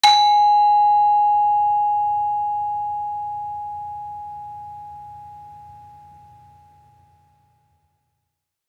HSS-Gamelan-1
Saron-1-A#4-f.wav